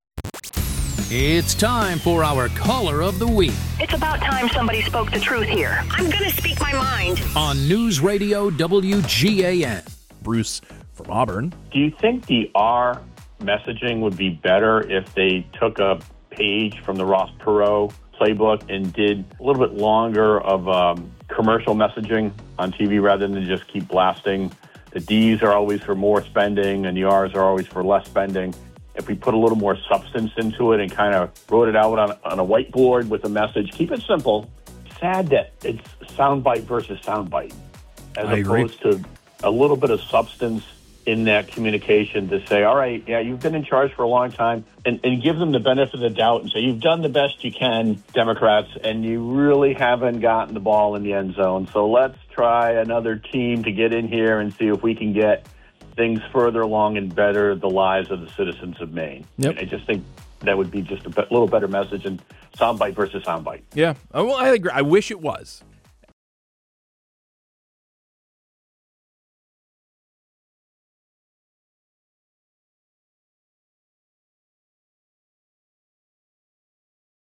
one person calling into the Morning News, who offers an interesting take, a great question or otherwise bring something to the discussion that is missing.